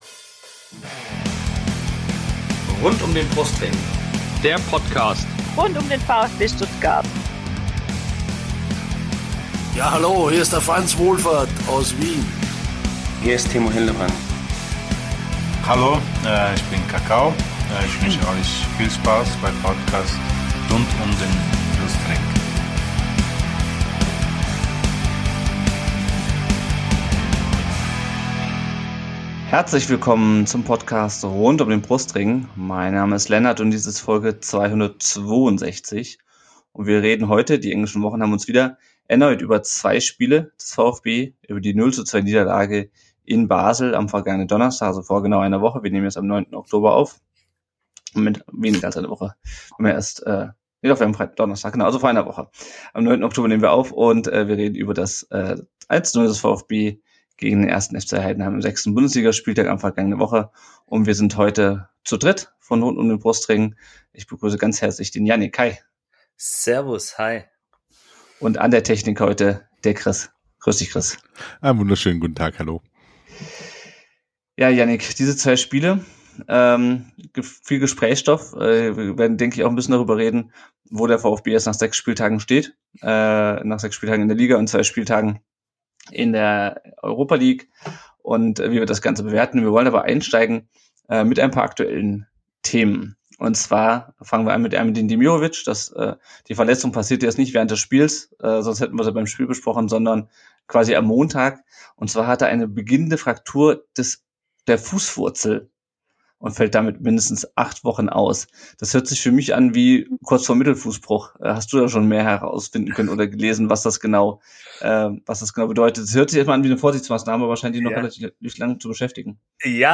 Auch diese Folge konntet Ihr live auf Twitch mitverfolgen!